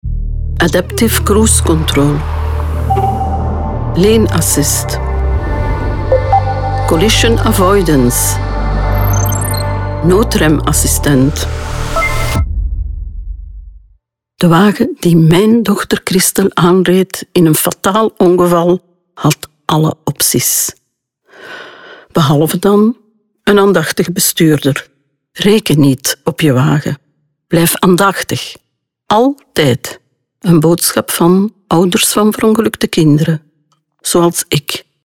Radiospots die klinken als stereotiepe autoreclame.
Radio 35" NL